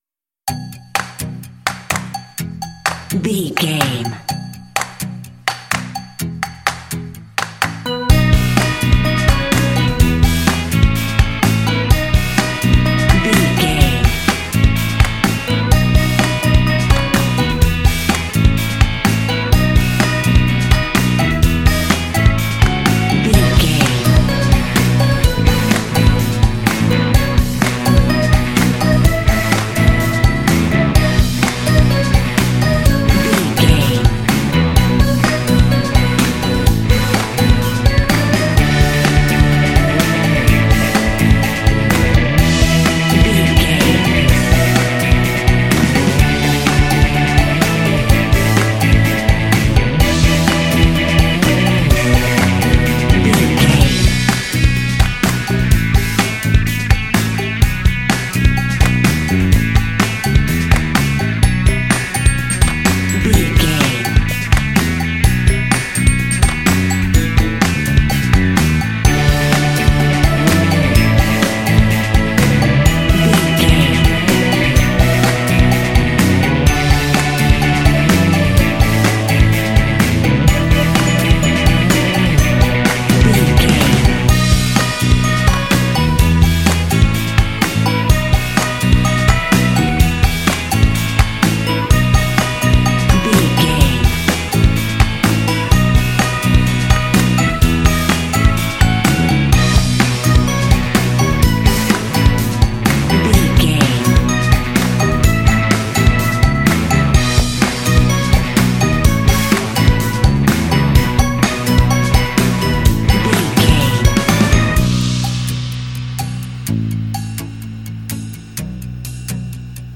This fun and lighthearted track features a funky organ.
Uplifting
Mixolydian
bouncy
electric guitar
drums
percussion
organ
bass guitar
rock
alternative
indie
blues